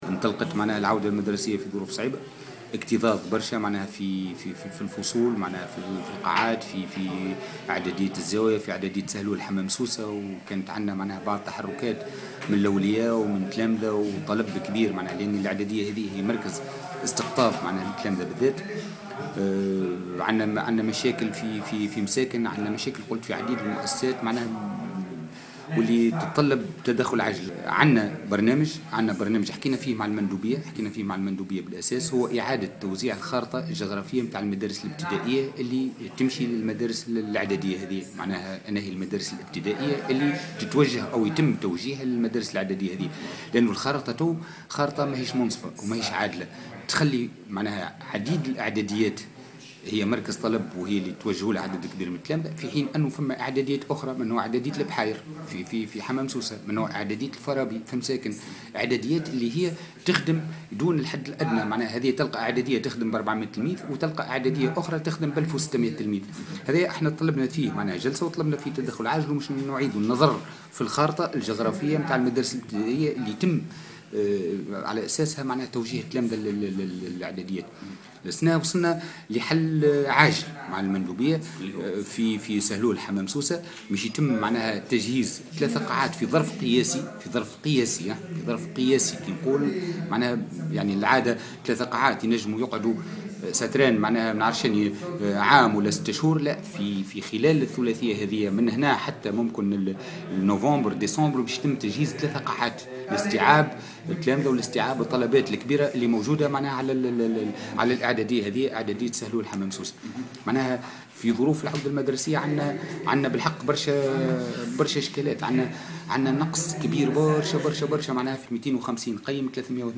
في تصريح لمبعوث الجوهرة اف ام خلال ندوة صحفية